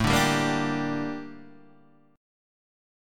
Gm/A chord